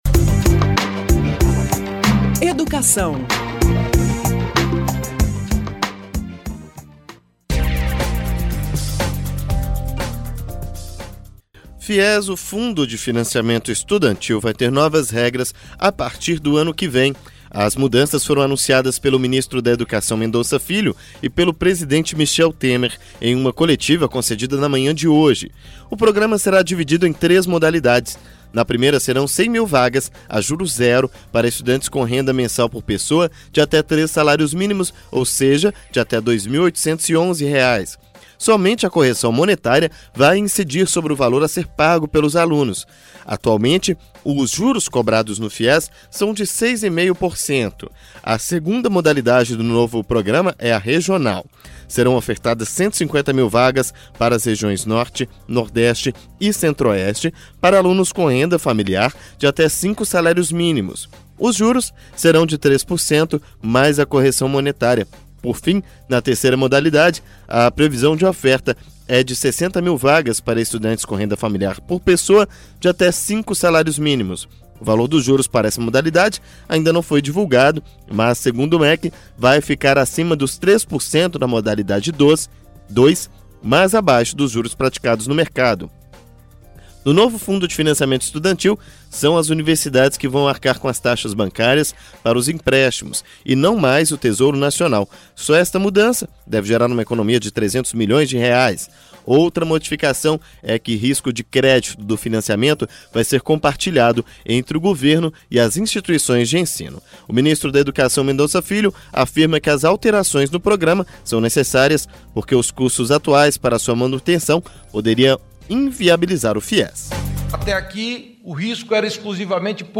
Alunos de baixa renda terão juro zero no pagamento das parcelas. Reportagem